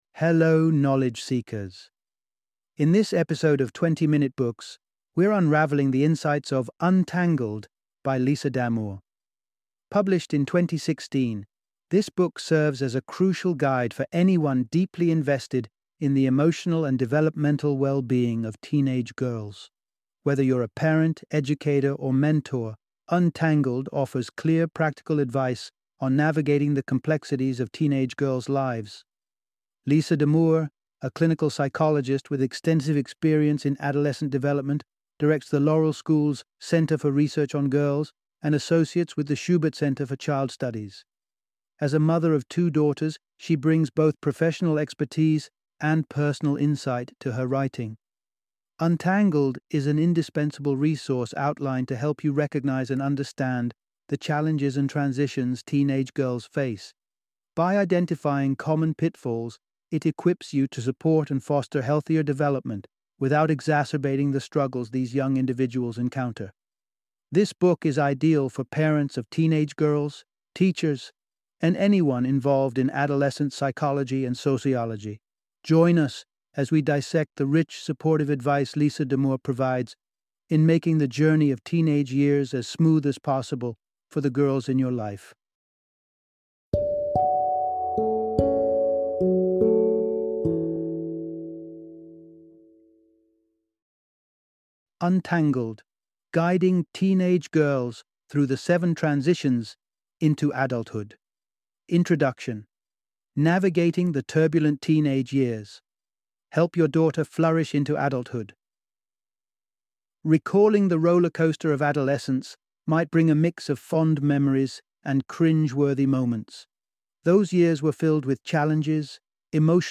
Untangled - Audiobook Summary